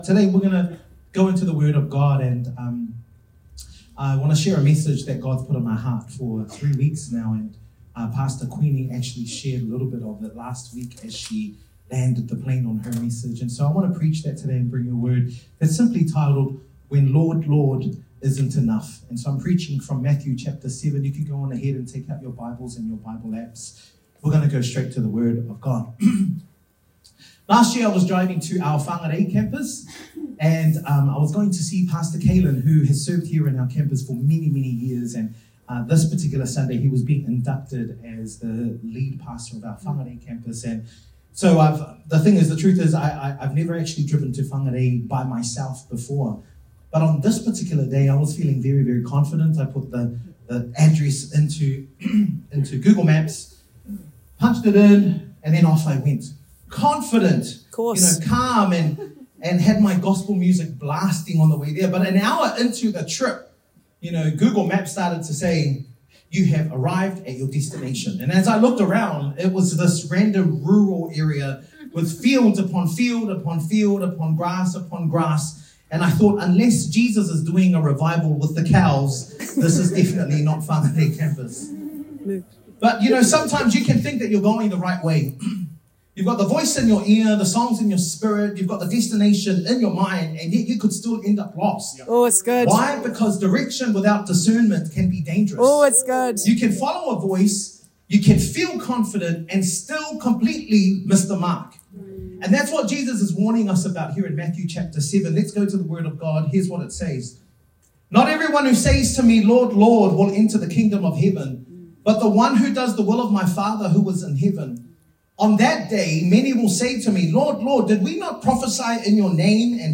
Sermon 8th June_01.mp3